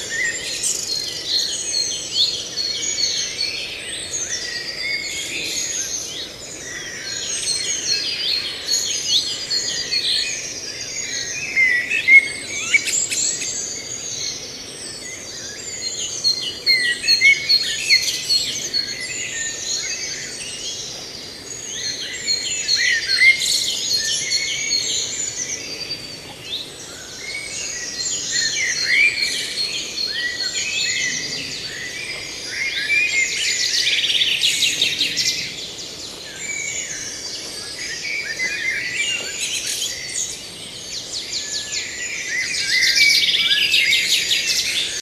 birds_screaming_loop.ogg